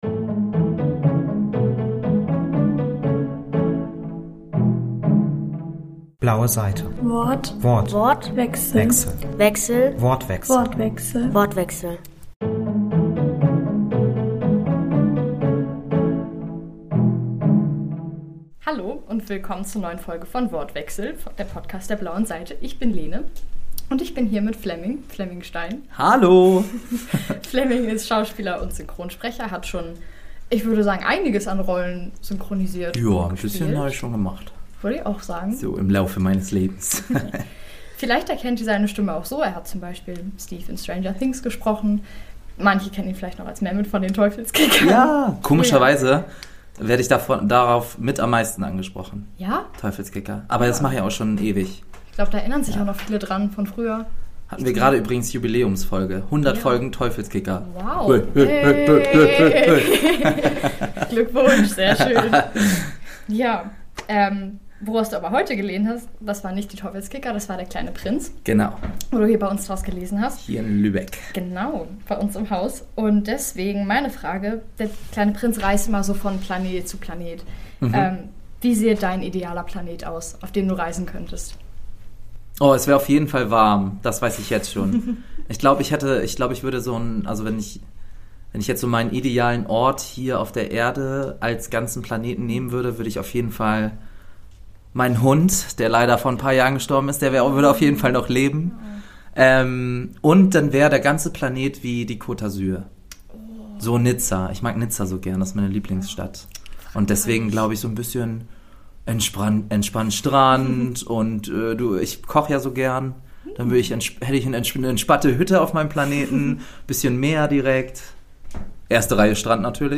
Bei den 18. Lübecker Jugendbuchtagen